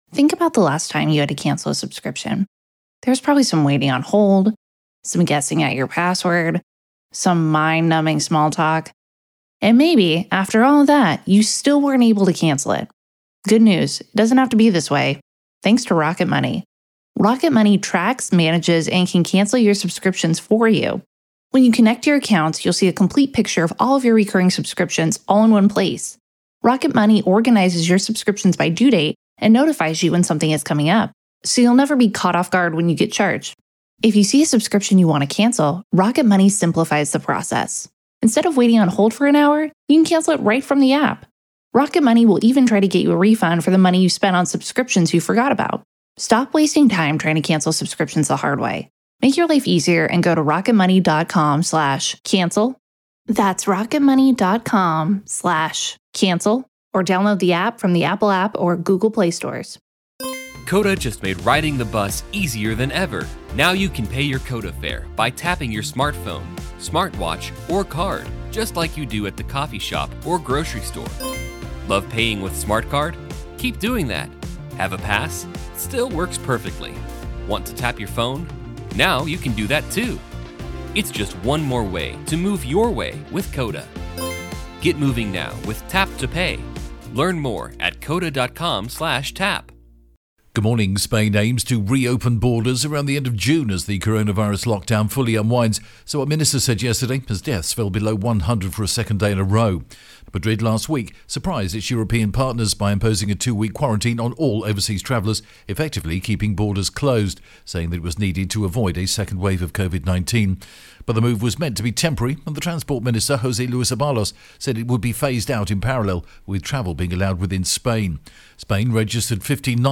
The latest Spanish news headlines in English: May 19th